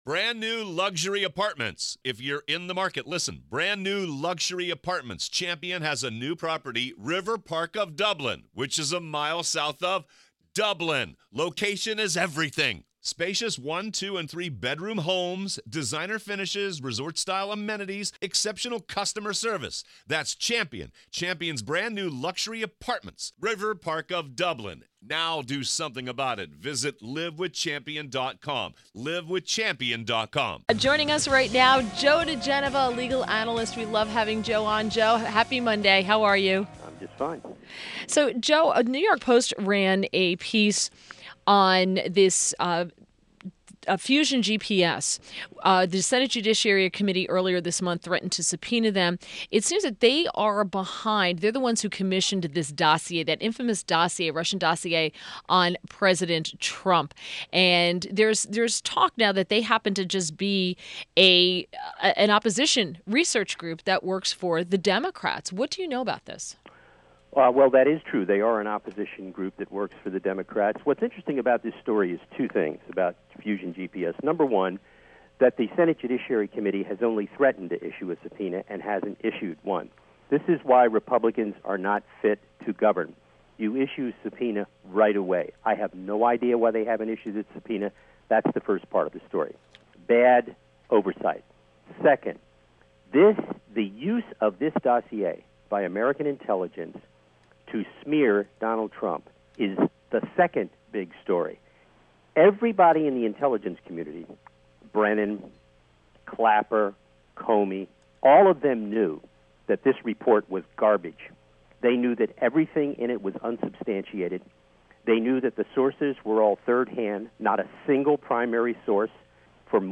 WMAL Interview - JOE DIGENOVA 06.26.17